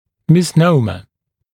[mɪs’nəumə][мис’ноумэ]неправильное употребление термина, некорректный термин